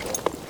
tac_gear_2.ogg